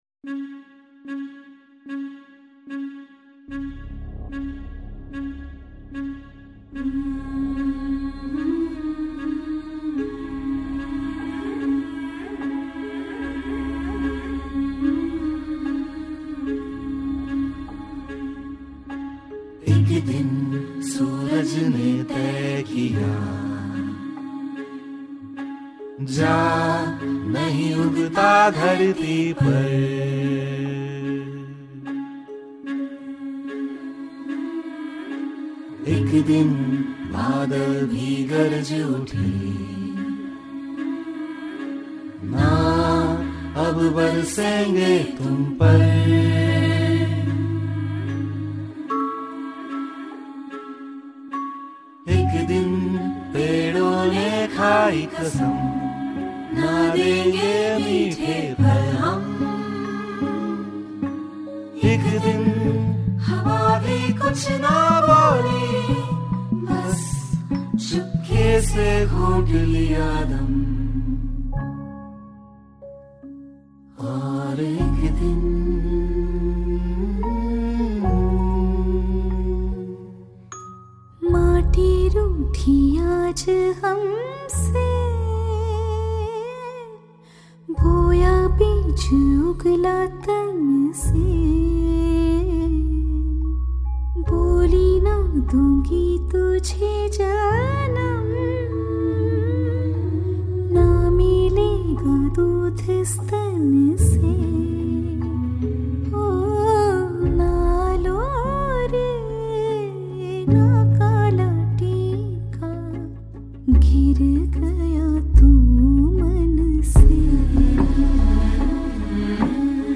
• Speaker: Singer